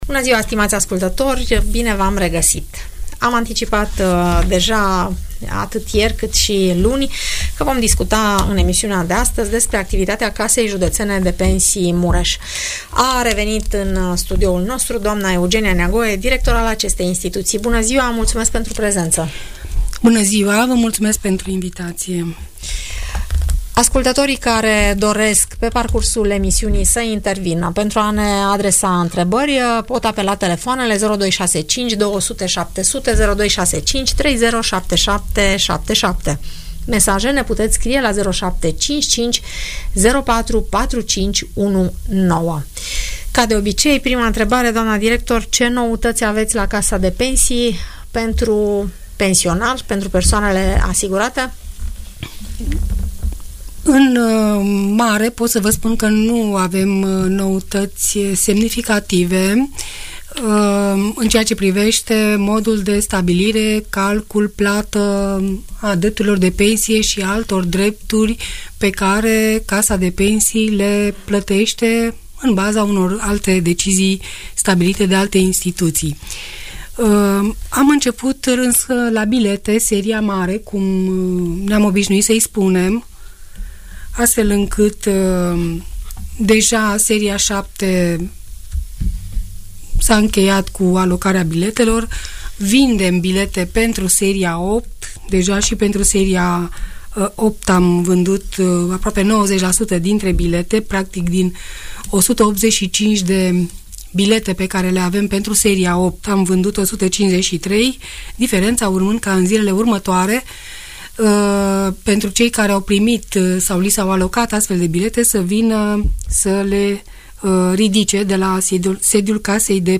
Audiență radio cu întrebări și răspunsuri despre toate tipurile de pensii, în emisiunea „Părerea ta” de la Radio Tg Mureș.